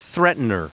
Prononciation du mot threatener en anglais (fichier audio)
Prononciation du mot : threatener